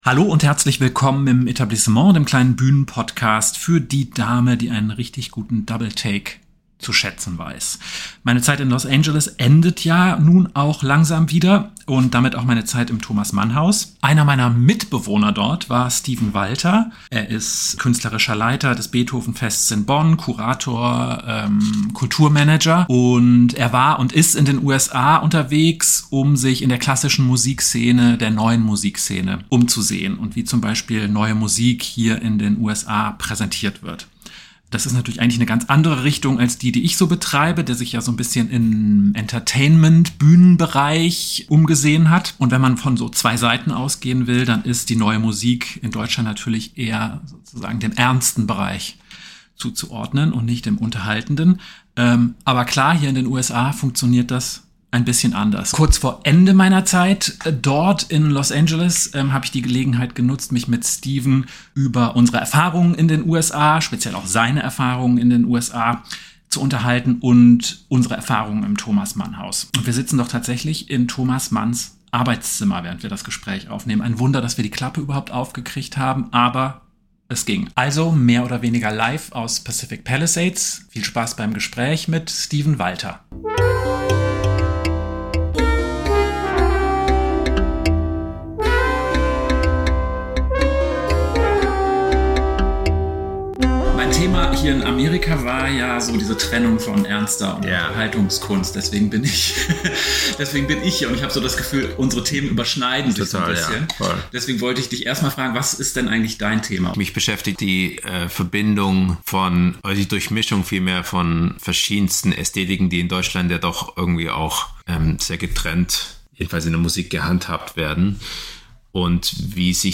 Wo überschneiden sich in den USA Entertainment und klassische Musik? Wie wird Kunst präsentiert, die in Deutschland sicher dem sogenannten "ernsten" Bereich zugerechnet würde? Kurz vor der Abreise also ein Rückblick live aus Thomas Manns Arbeitszimmer.